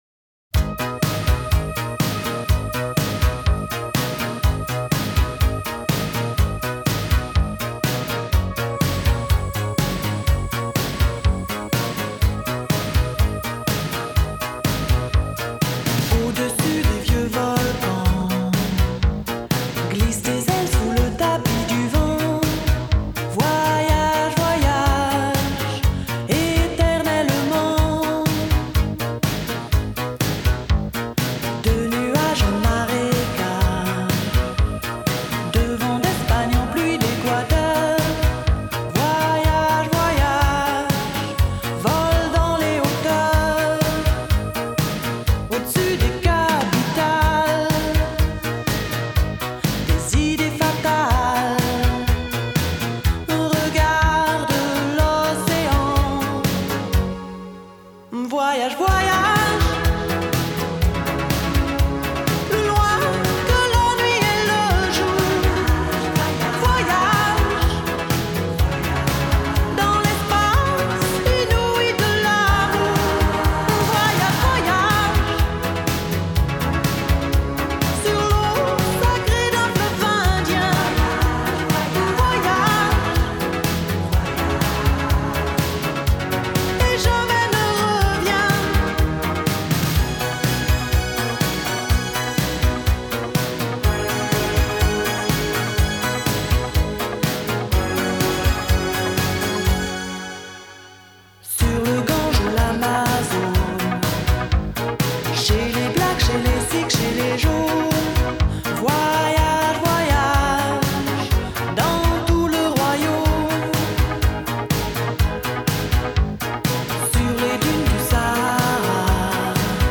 поп-музыка, рок-музыка